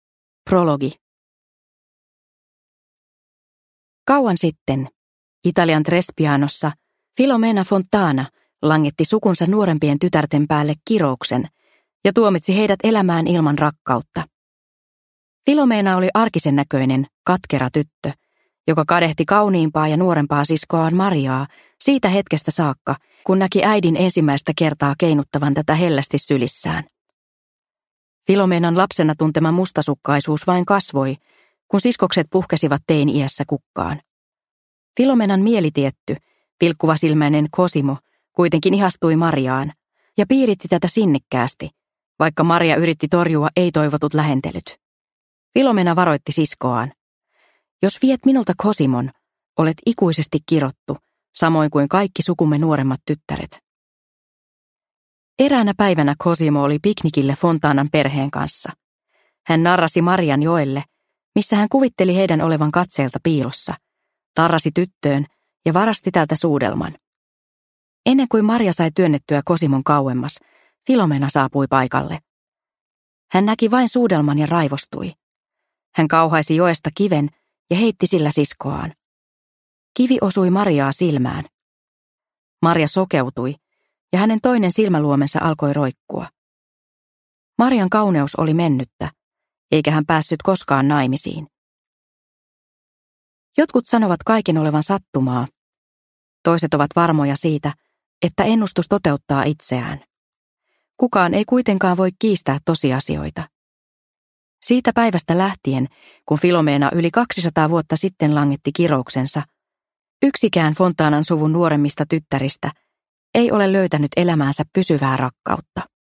Toscanan tytöt – Ljudbok – Laddas ner